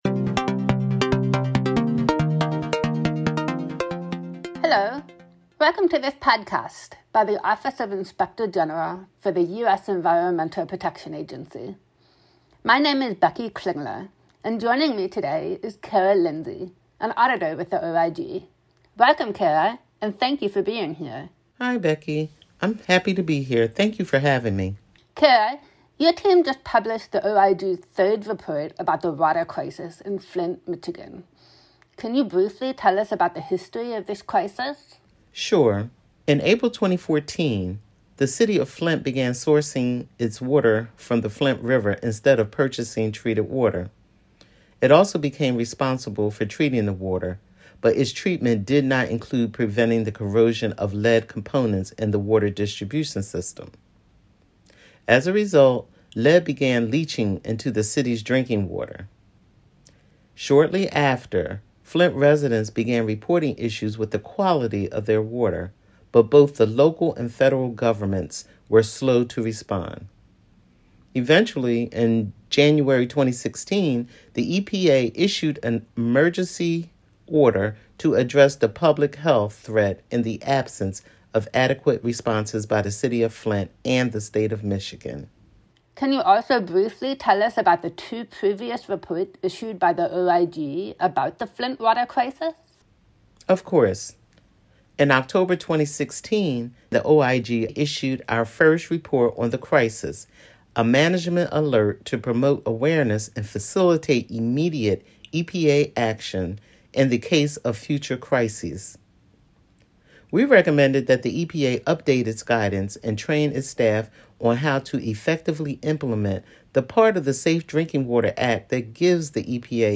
Listen to our staff talk about their latest audit reports, investigative functions and other initiatives.